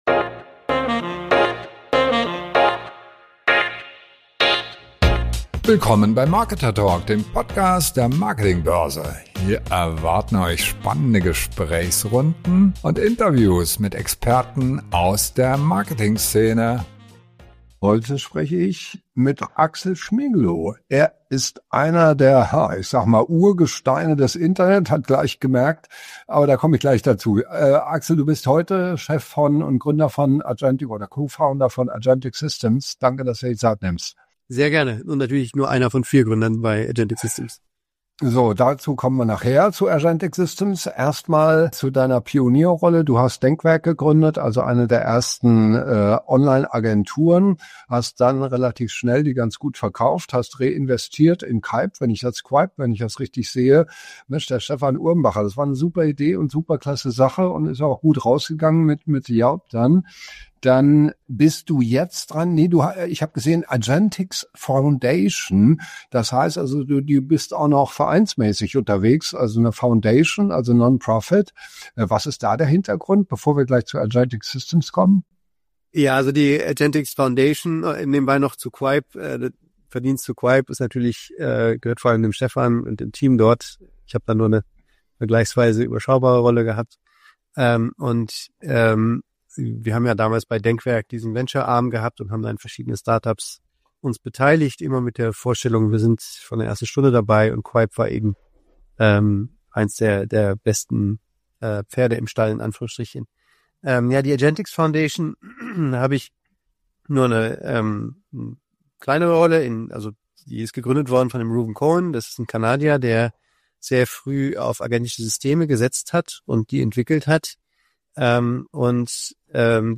Handlungsfähige KI ist nicht Science Fiction – es ist bereits Realität. Warum Unternehmen dringend ihre Architektur überdenken müssen, bevor große Plattformen ihre Daten und ihr Wissen kontrollieren. Ein Gespräch über „Agents Commerce", Souveränität und die nächste große Markttransformation.